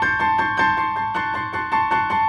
GS_Piano_105-A1.wav